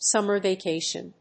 音節sùmmer vacátion